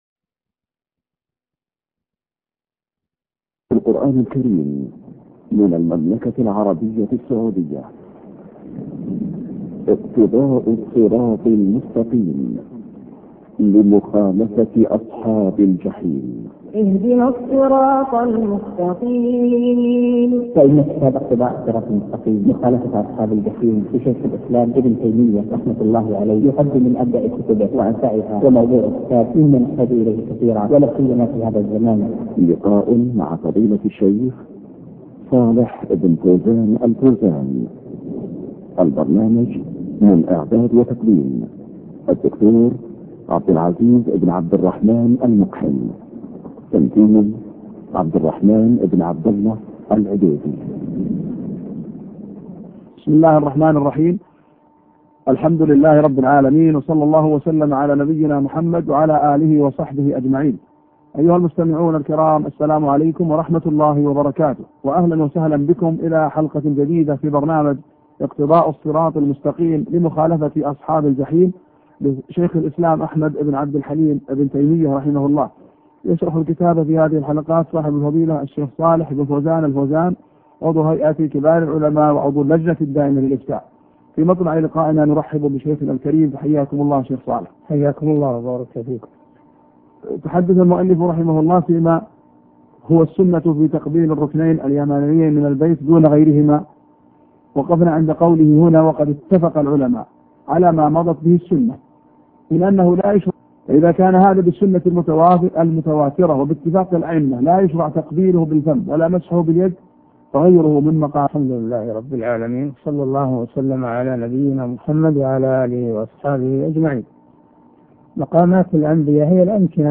شرح اقتضاء الصراط المستقيم الدرس 130